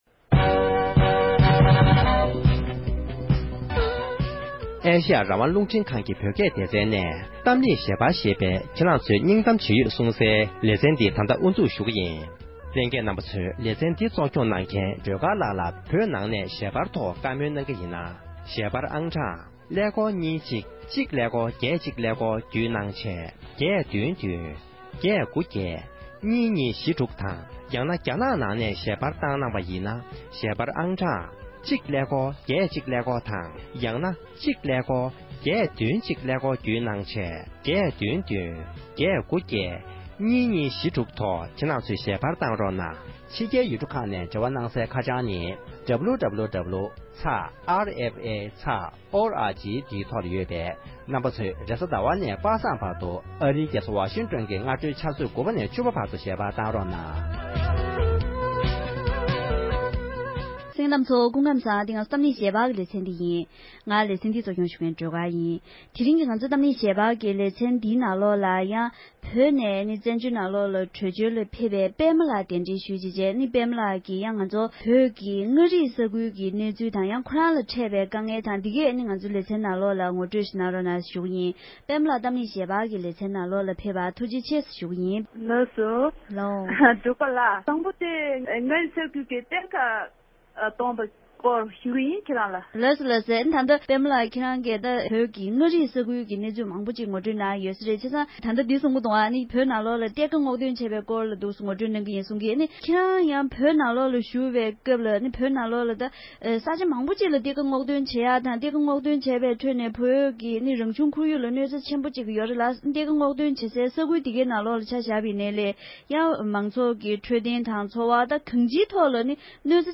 ཉེ་ཆར་བཙན་བྱོལ་དུ་འབྱོར་བའི་བོད་མི་ཞིག་གིས་བོད་ནང་གི་གནས་སྟངས་འགྲེལ་བརྗོད་གནང་བའི་གནས་ཚུལ།